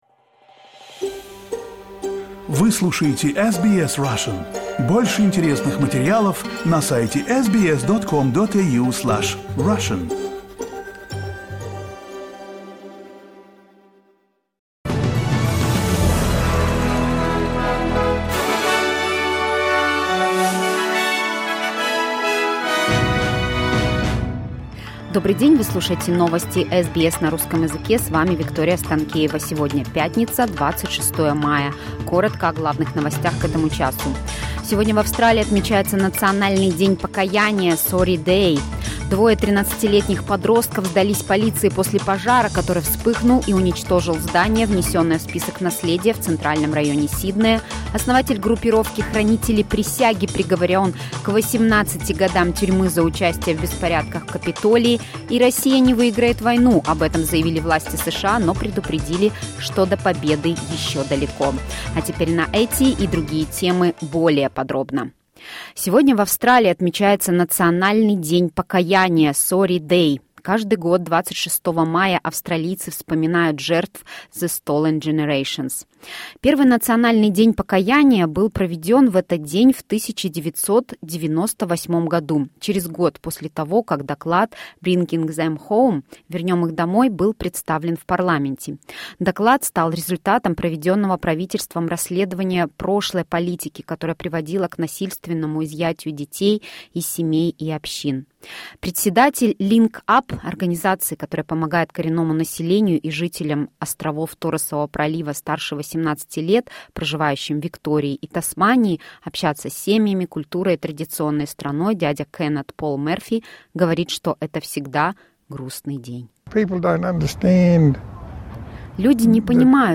Listen to the latest news headlines in Australia from SBS Russian